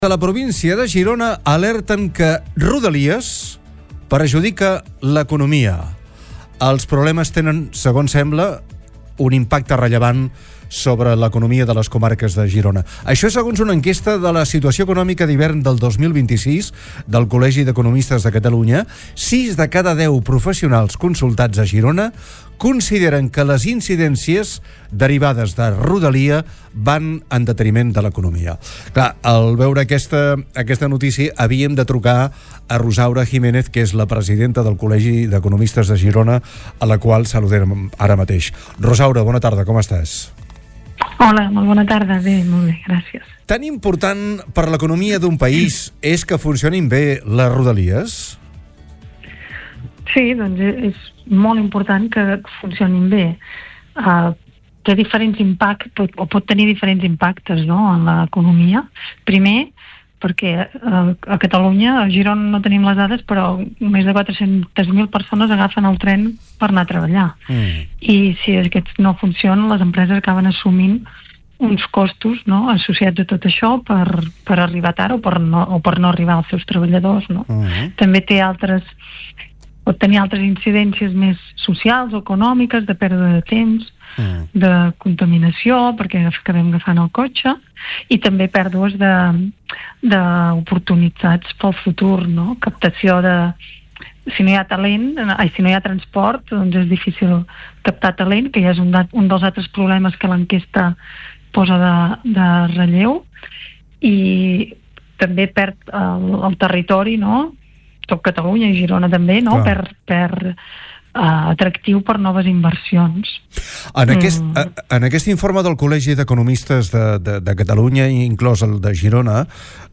ha estat entrevista